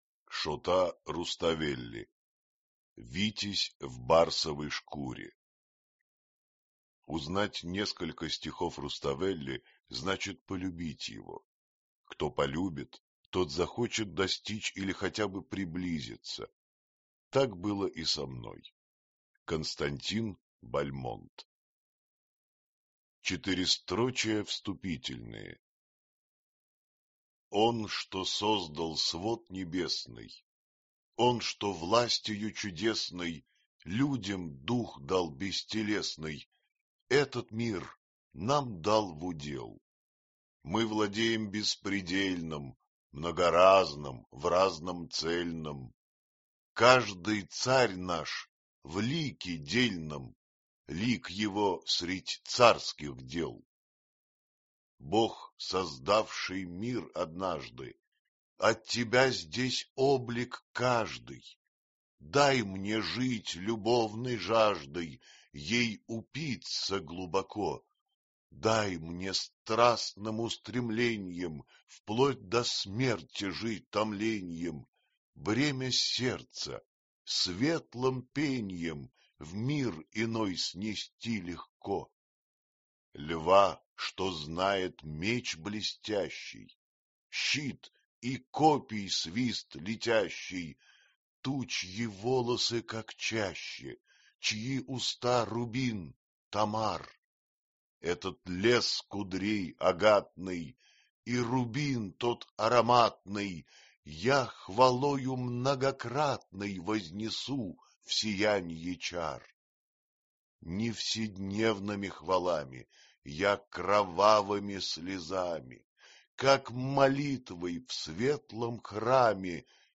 Аудиокнига Витязь в барсовой шкуре | Библиотека аудиокниг